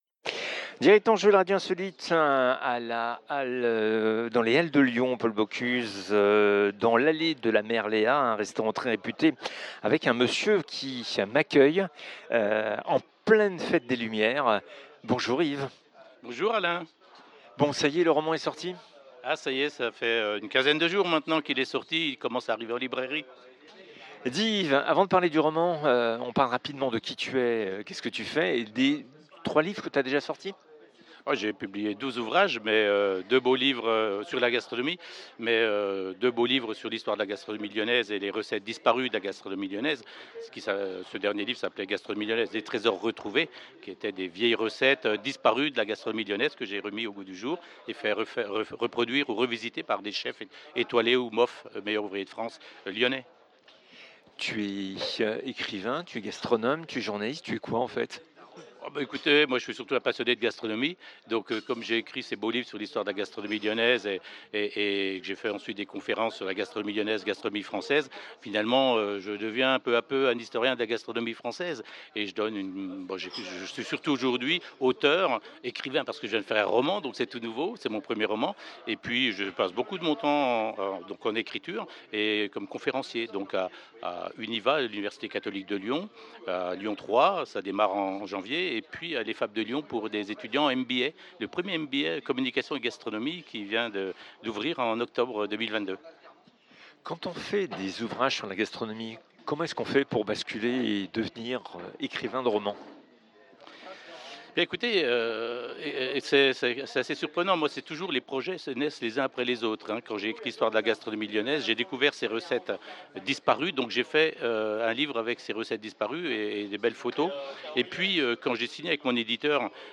un monde sans Paul Bocuse en direct des Halles Paul Bocuse à Lyon